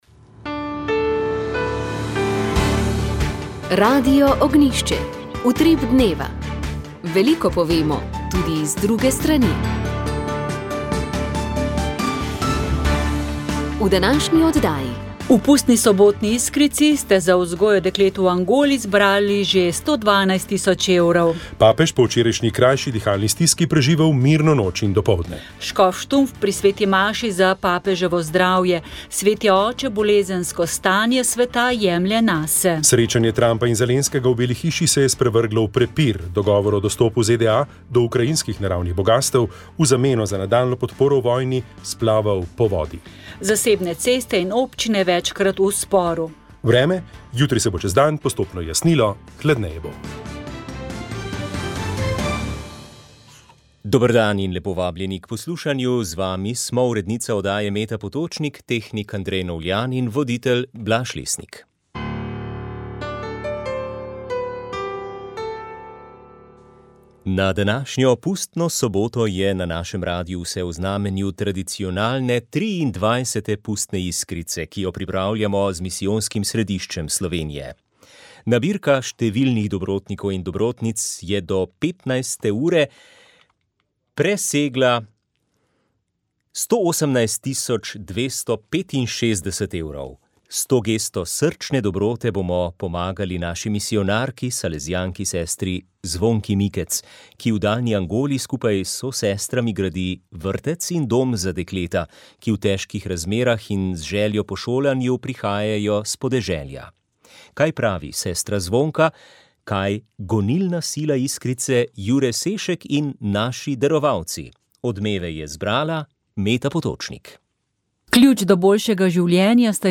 Škof Peter Štumpf v pogovoru za Radio Ognjišče o pomenu molitve za papeža Frančiška in o vtisih prvega meseca v novi škofiji.
Radio Ognjišče info novice Informativne oddaje VEČ ...